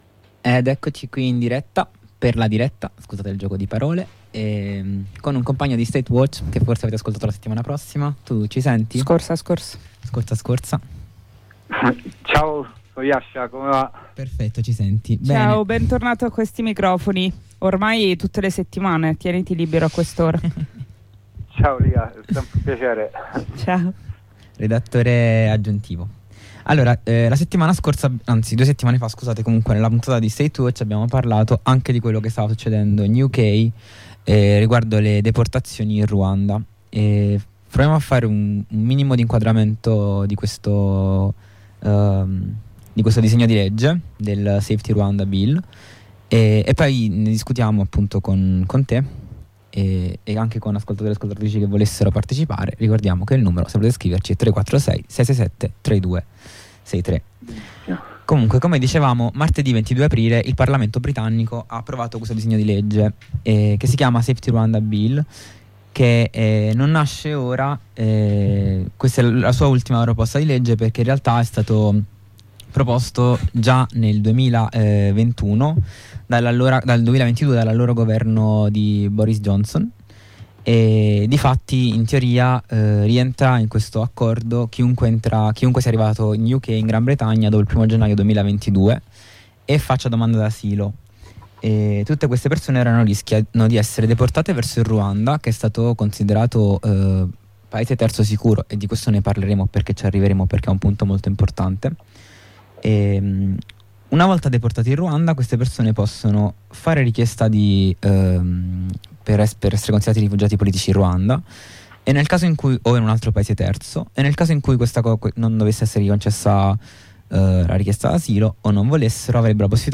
In questo approfondimento andato in onda su Harraga abbiamo posto uno sguardo, assieme ad un’attivista d’inchiesta di Statewatch, sul Safety Rwanda Bill.